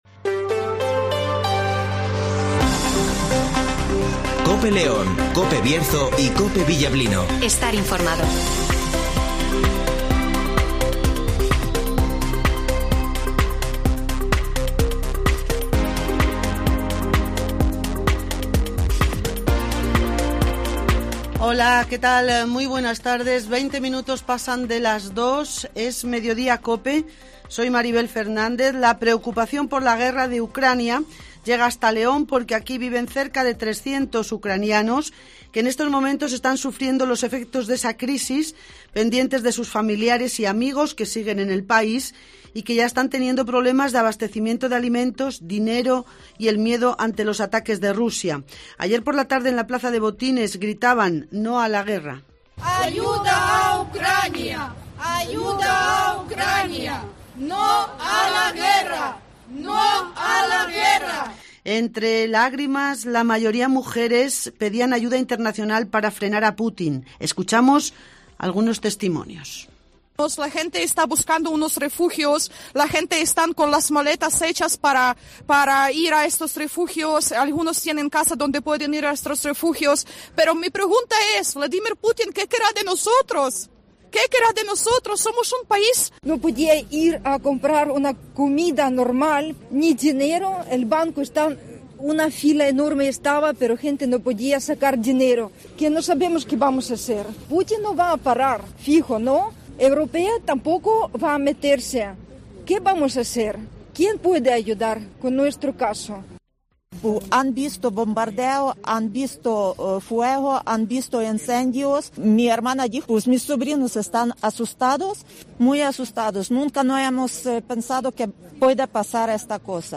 - Gritos Ucranianos
- Hablan Mujeres Ucranianas
- Matías Llorente ( Vicepresidente de la Diputación )
- Santiago Dorado ( Diputado de Hacienda )
- Eduardo Morán ( Presidente de la Diputación )